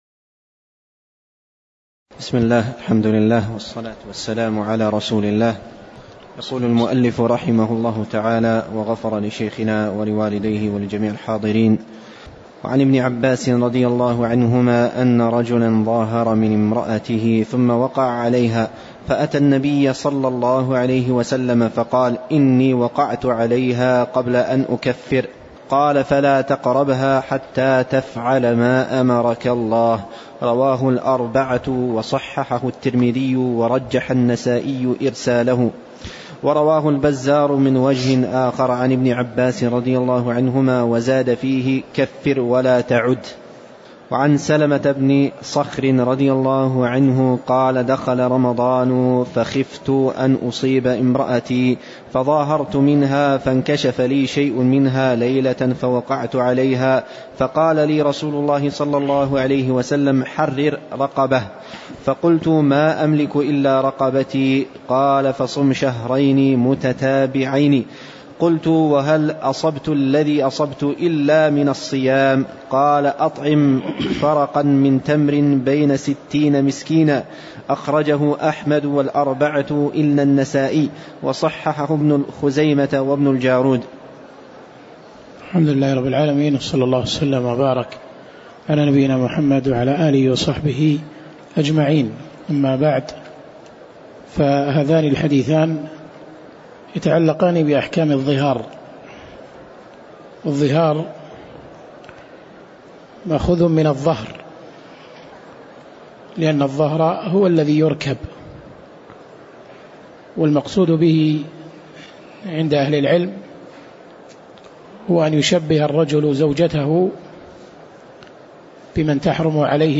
تاريخ النشر ٢٩ جمادى الآخرة ١٤٣٨ هـ المكان: المسجد النبوي الشيخ